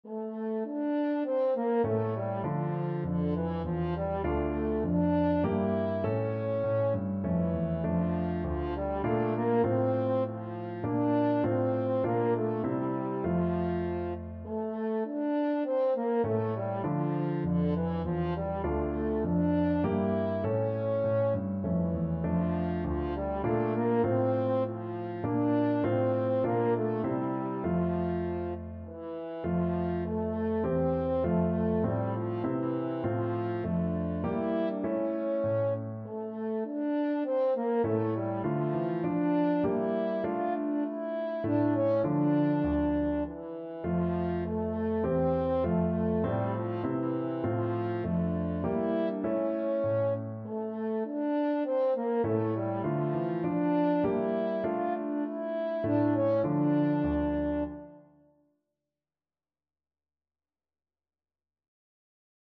French Horn
3/4 (View more 3/4 Music)
D minor (Sounding Pitch) A minor (French Horn in F) (View more D minor Music for French Horn )
Moderato
Classical (View more Classical French Horn Music)